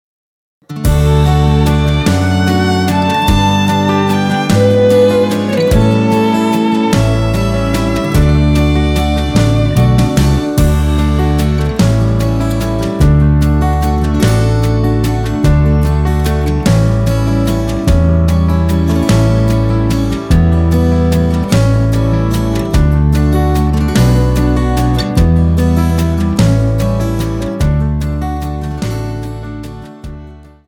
Tonart:D ohne Chor